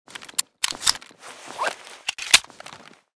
cz52_reload.ogg.bak